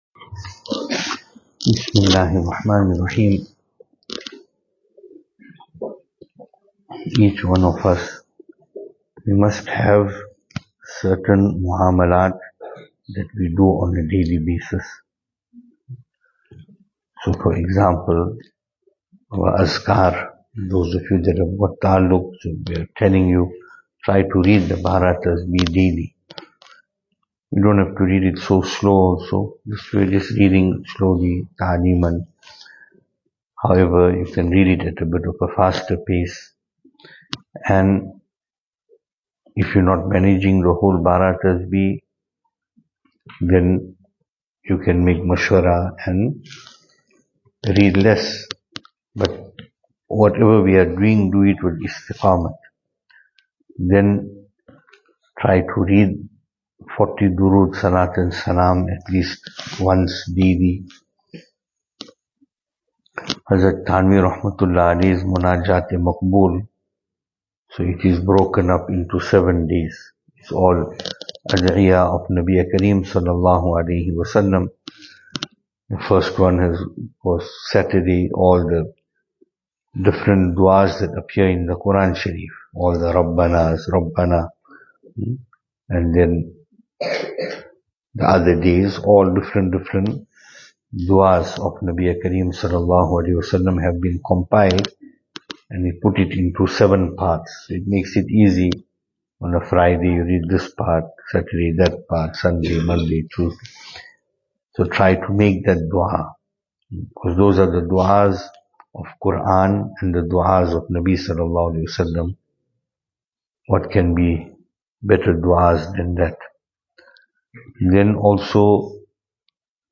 Overnight Programme – After Fajr
2025-05-18 Overnight Programme – After Fajr Venue: Albert Falls , Madressa Isha'atul Haq Series: Islahi Majlis Service Type: Overnight Topics: Islahi Majlis « Short Audio Clip – Agri fairs in the name of Qurbani.